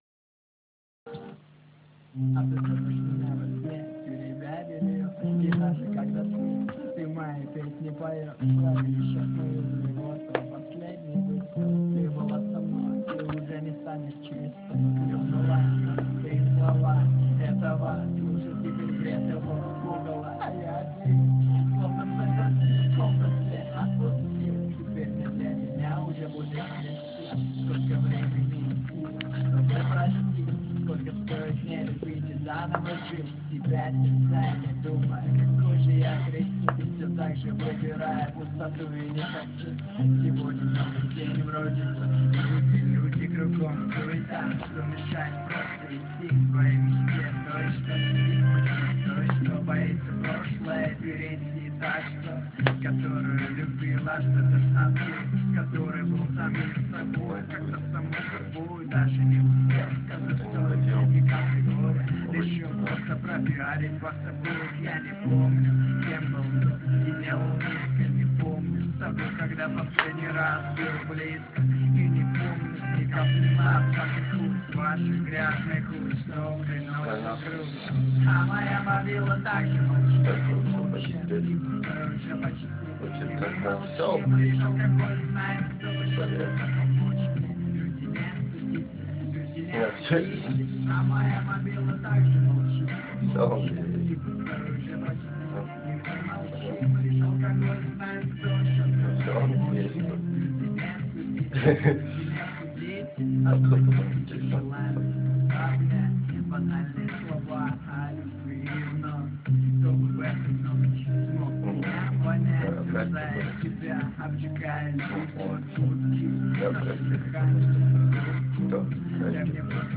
неслышно нехрена
Недорепер какой-то.